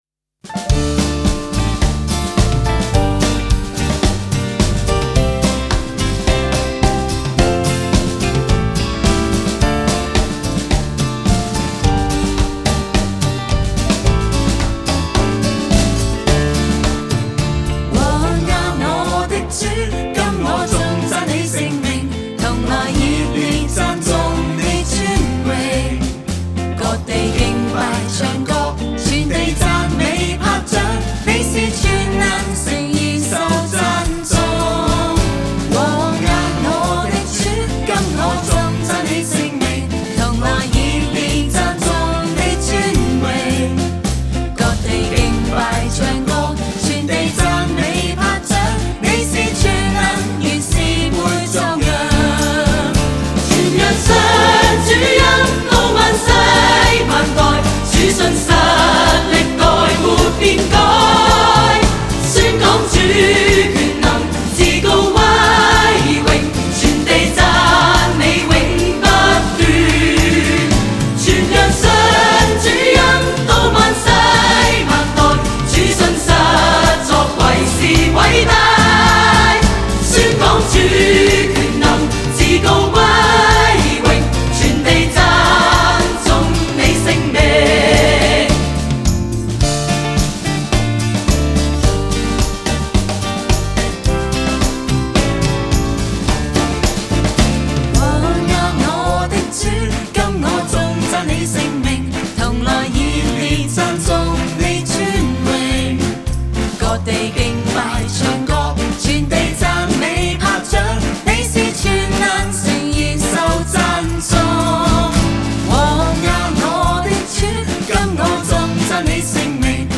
前奏 → 主歌 → 副歌 → 主歌 → 副歌 → 副歌(放慢)